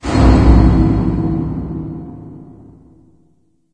Звук глухой удар по металу    .